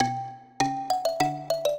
mbira
minuet2-8.wav